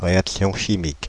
Ääntäminen
Ääntäminen France (Île-de-France): IPA: /ʁe.ak.sjɔ̃ ʃi.mik/ Tuntematon aksentti: IPA: /ʁe.ak.sjɔ̃.ʃi.mik/ Haettu sana löytyi näillä lähdekielillä: ranska Käännös Konteksti Substantiivit 1. chemical reaction kemia Suku: f .